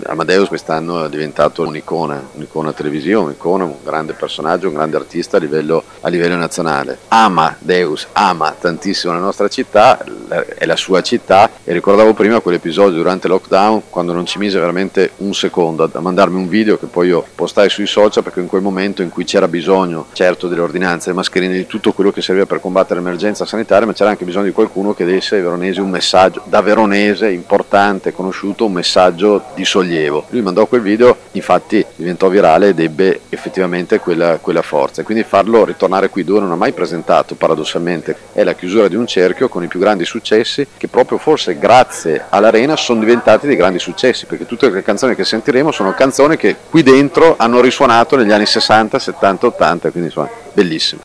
Il Sindaco di Verona Federico Sboarina: